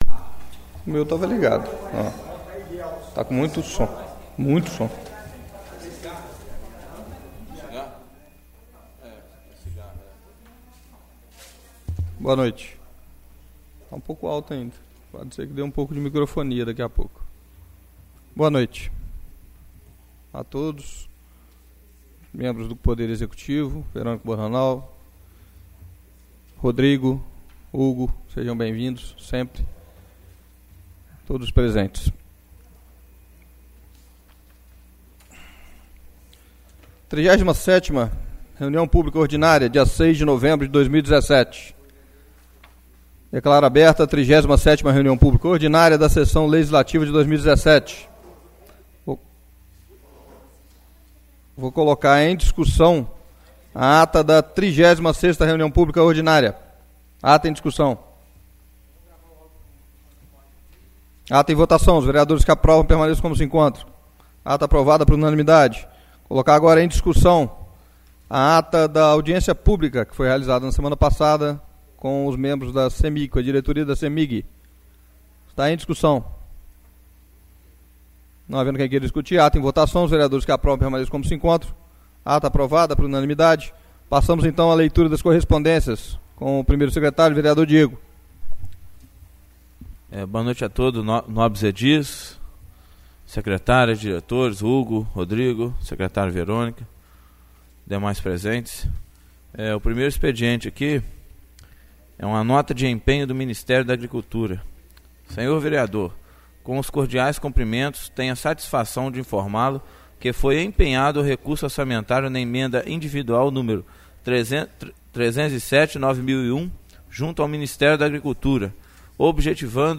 37° Reunião Publica Ordinária 06/11/2017.